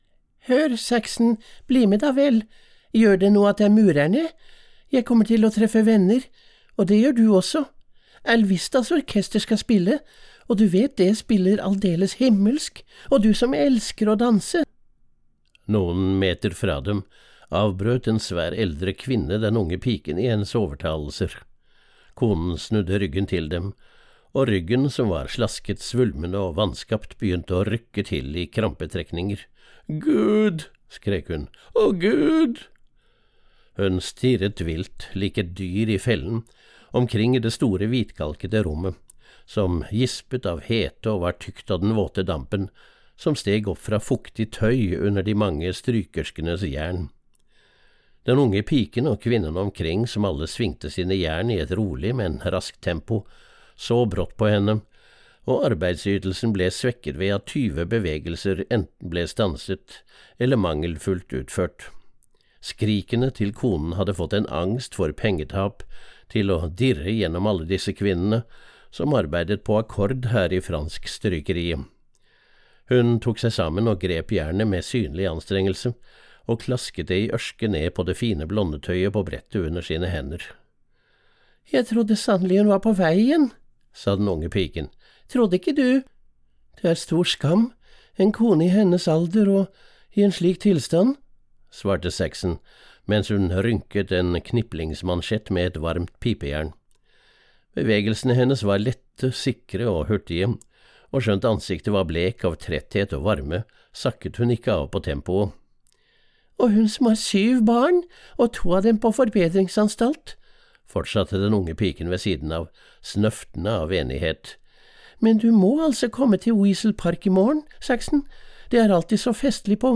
Månedalen - bok 1 (lydbok) av Jack London